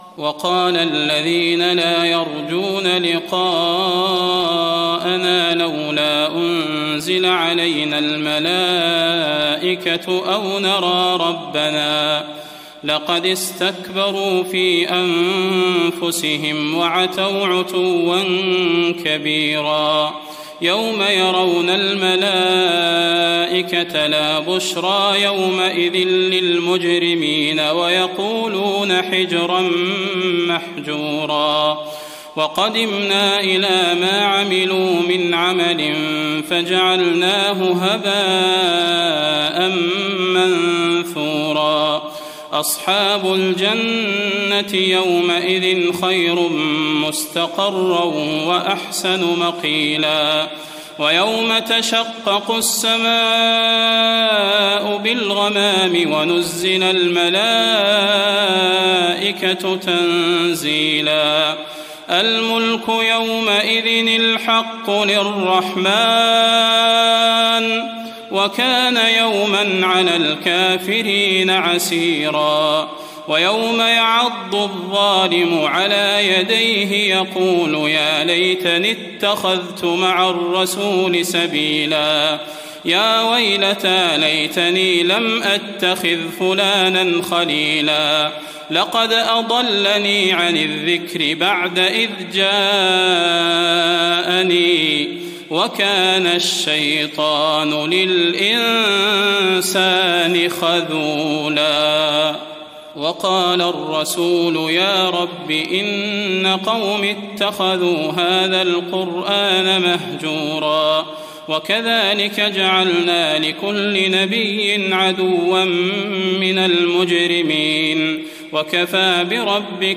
تراويح الليلة الثامنة عشر رمضان 1419هـ من سورتي الفرقان (21-77) و الشعراء (1-104) Taraweeh 18th night Ramadan 1419H from Surah Al-Furqaan and Ash-Shu'araa > تراويح الحرم النبوي عام 1419 🕌 > التراويح - تلاوات الحرمين